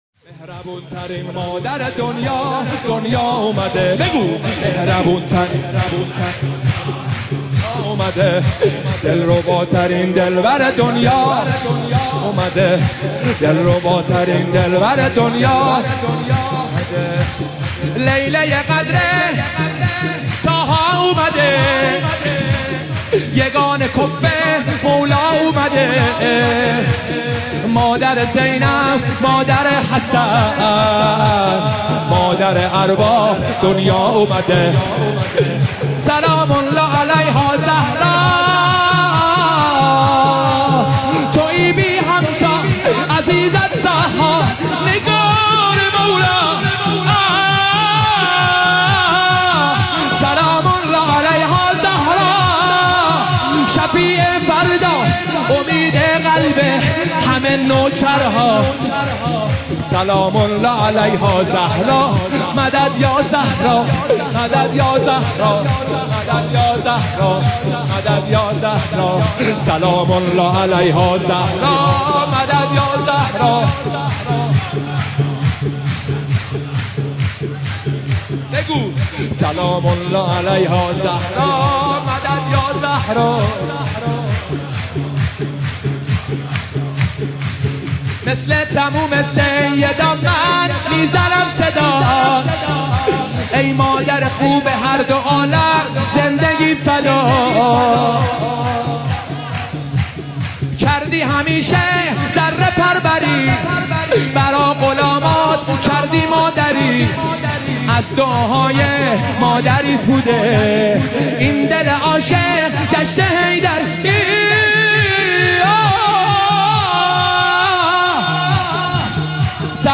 مولودی حضرت زهرا شاد